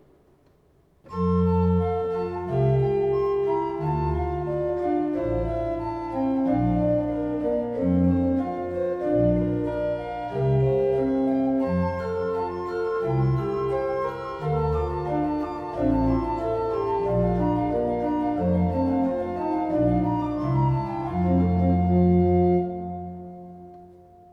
"An die Pfeifen, fertig, los!" Minierzählkonzert Kinderorgeltag am 09. August
Orgelthema 1